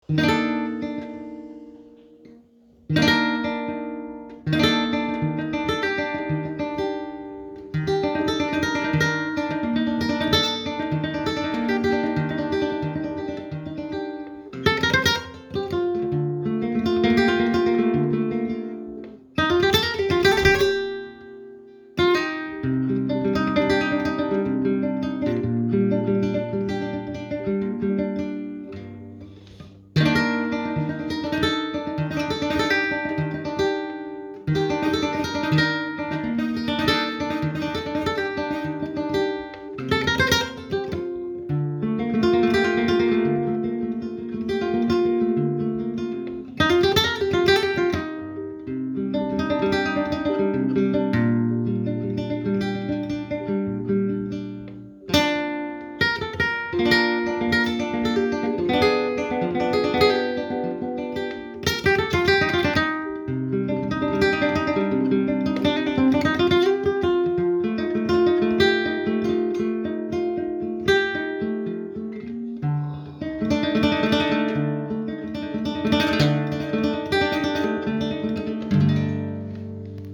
guitarra
Es algo nuevo para mí, me salgo del flamenco, aunque tiene mis pinceladas flamencas, porque yo vengo de ahí y no puedo evitarlo", afirma.
introduce canciones y toca para poner música a canciones de cantautores y expresar melodías con muchos arpegios para subrayar su propio estilo.